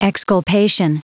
Prononciation du mot exculpation en anglais (fichier audio)
Prononciation du mot : exculpation